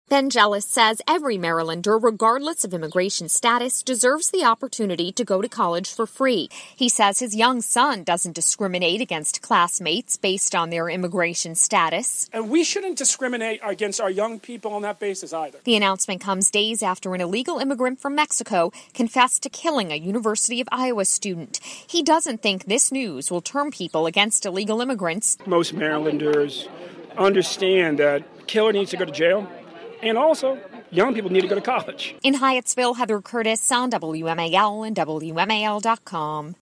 Speaking along with immigrant advocates, including CASA and DACA recipients, at a press conference Wednesday, Ben Jealous said every Marylander, regardless of immigration status, deserves the opportunity to go to college.